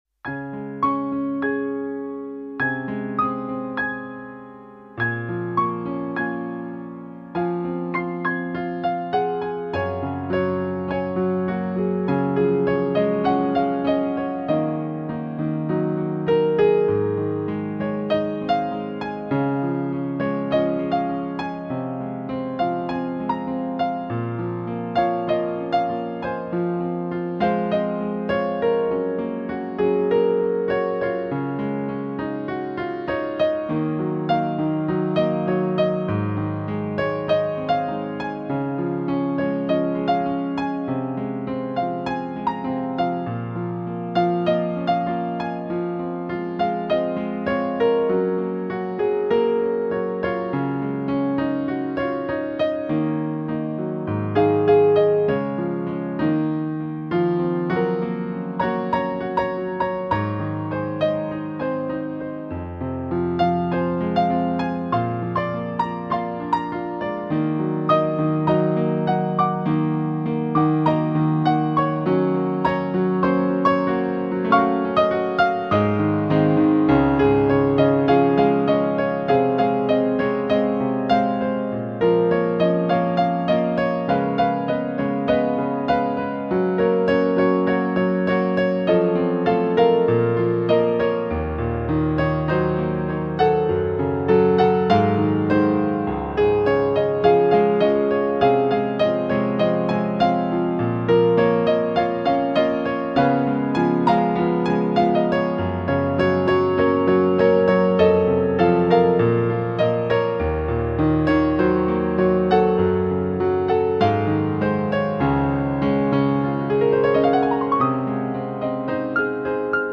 黑白琴键上的恋曲